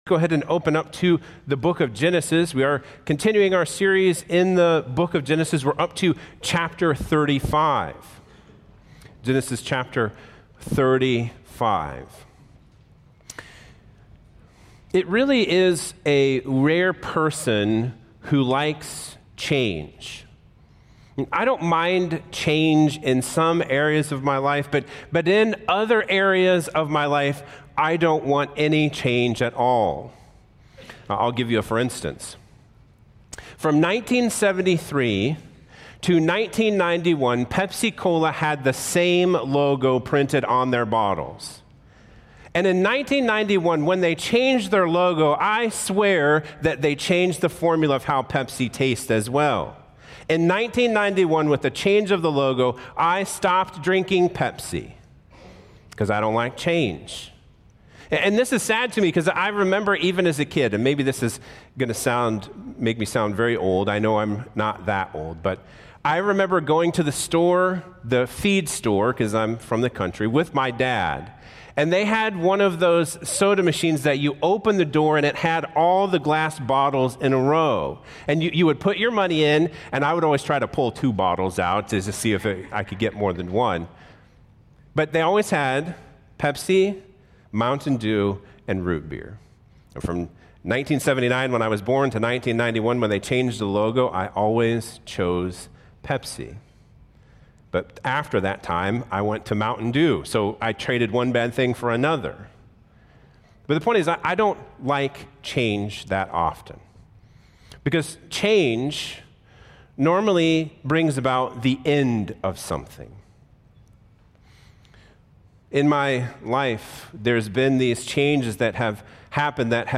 Sermons | CrossBridge Church